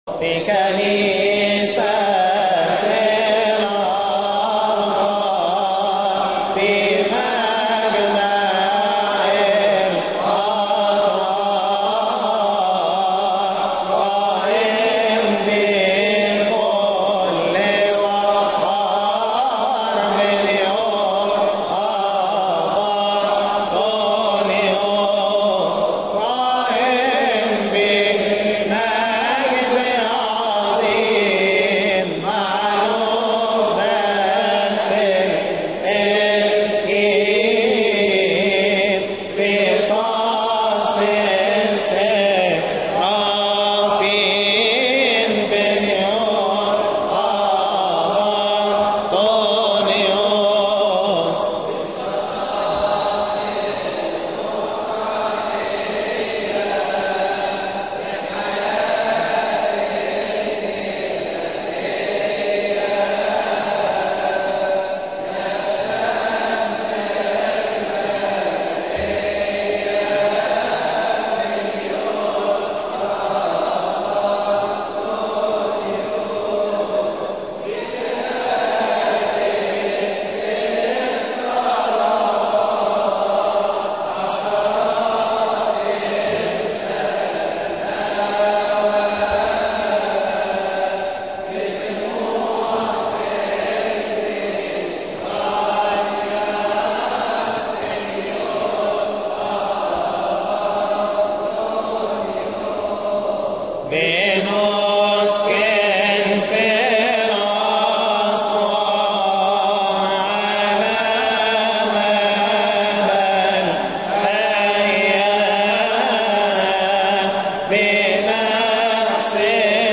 فى كنيسة الابكار